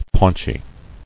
[paunch·y]